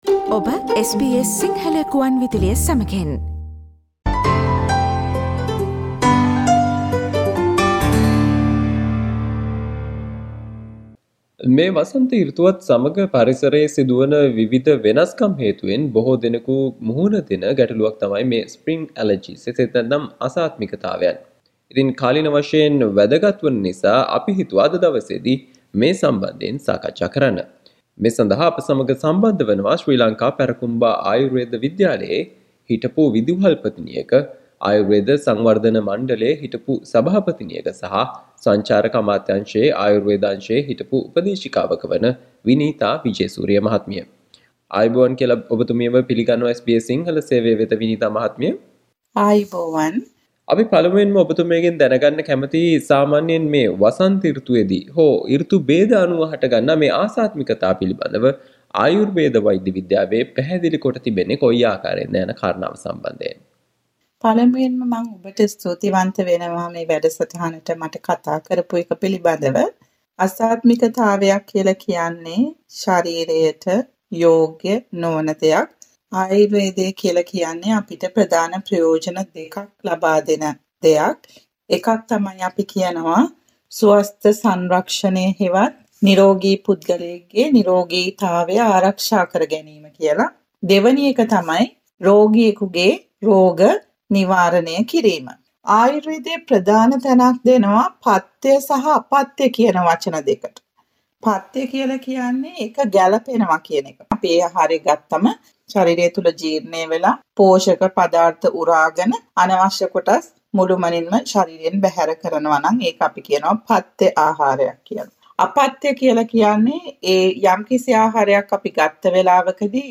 ඕස්ට්‍රේලියාවේ දැන් පවතින මෙම වසන්ත සෘතුවත් සමග බොහෝ දෙනෙකුට වැළඳිය හැකි Spring Allergies නොහොත් ආසාත්මිකතා පිළිබඳව ආයුර්වේද වෛද්‍ය විද්‍යාවේ පවතින ප්‍රතිකාර පිළිබඳව දැනුවත් වෙන්න මෙම කෙටි සාකච්චාවට සවන්දෙන්න ..